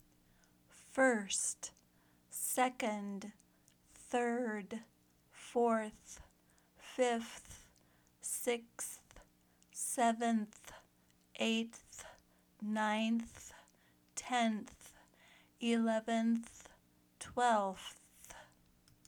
All ordinal numbers (except first, second, and third) end with the unvoiced th sound.
Here are the ordinal numbers from one to twelve with the IPA pronunciations.
Pronounce Ordinal Numbers